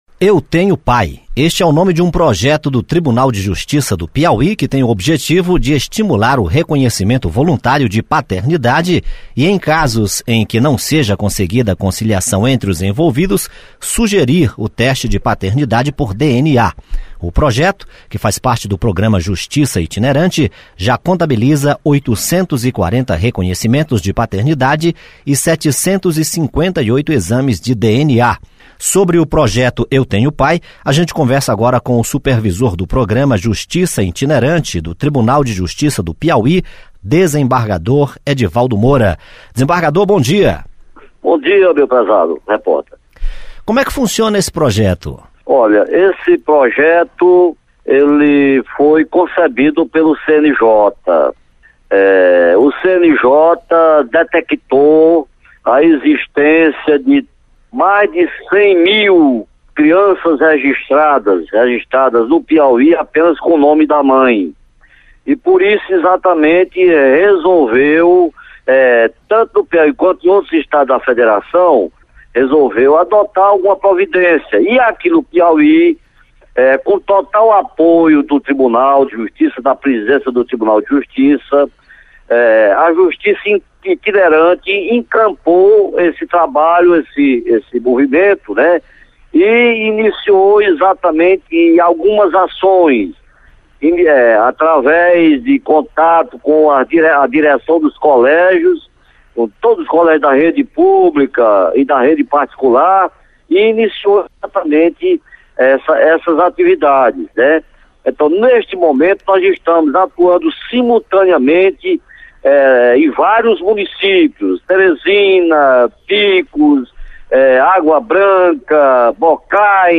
Entrevista com o coordenador do projeto "Eu Tenho Pai", desembargador Edvaldo Moura.